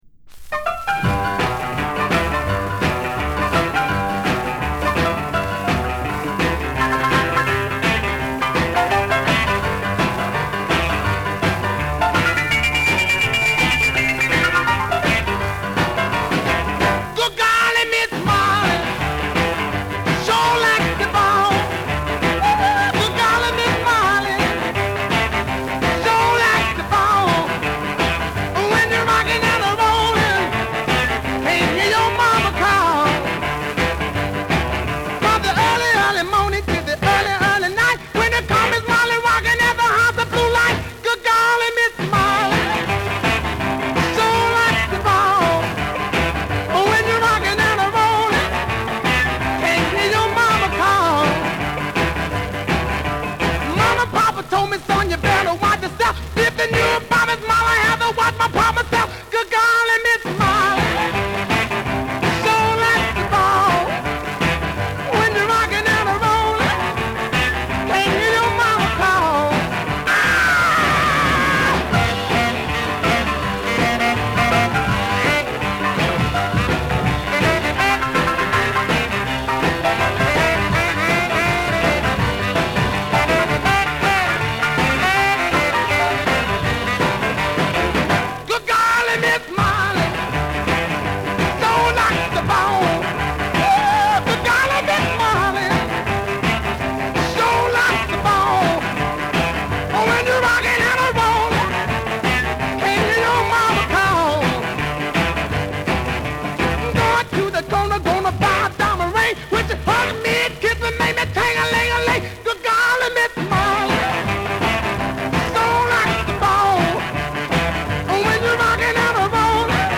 なんてプリミティヴなサウンドなのだろう。
• ROCK'N'ROLL / OLDIES